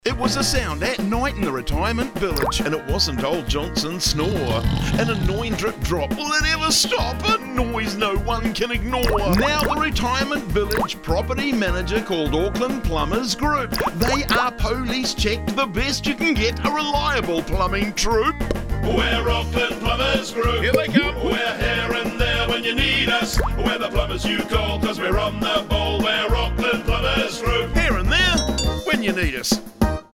Our latest radio advert highlights our work with retirement villages and aged-care facilities.
Radio Jingle
Radio-advert-Retirement-Villages-0325.mp3